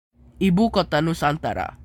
Indonesian pronounciation of "nusantara"